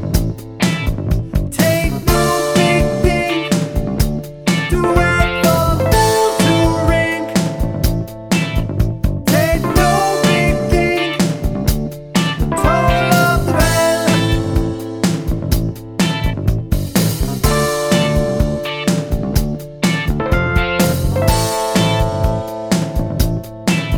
Intro Cut Down Pop (1970s) 3:44 Buy £1.50